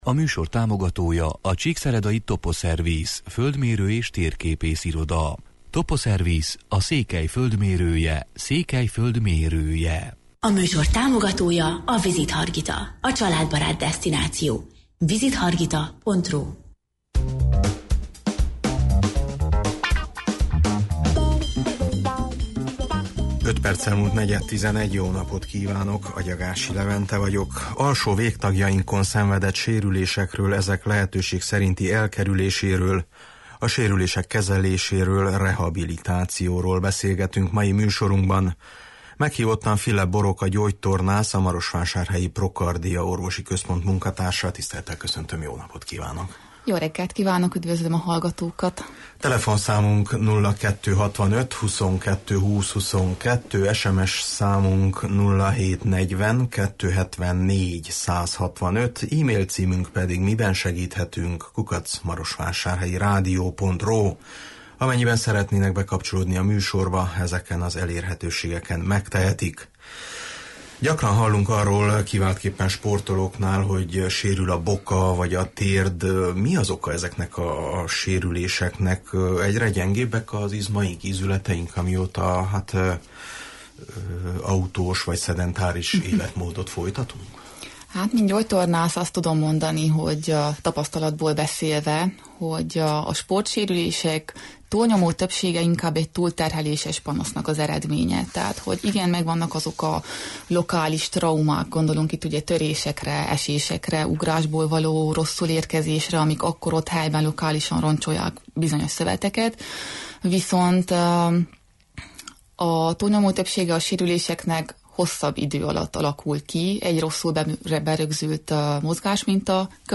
Alsó végtagjainkon szenvedett sérülésekről, ezek lehetőség szerinti elkerüléséről, a sérülések kezeléséről, rehabilitációról beszélgetünk mai műsorunkban.